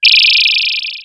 Medicscanner2.wav